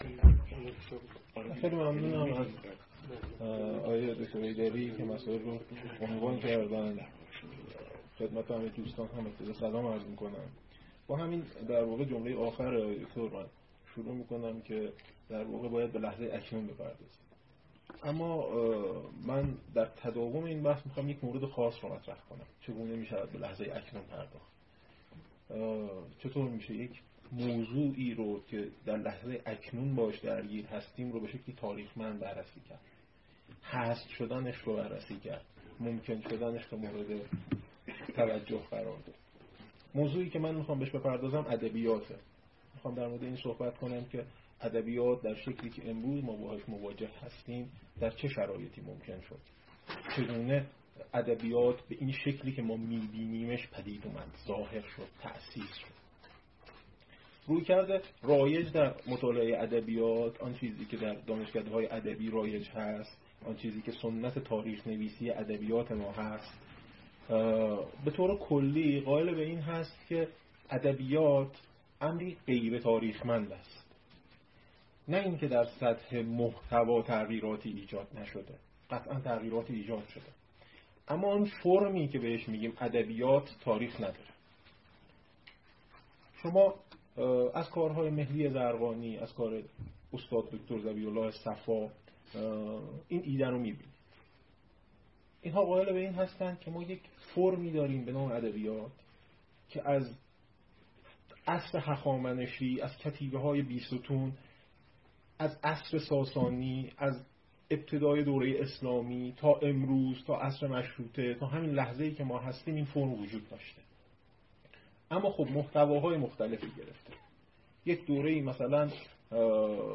فایل زیر سخنرانی
در نشست تاریخ انتقادی و علوم انسانی ایرانی است که در دانشگاه شهید بهشتی برگزار شد.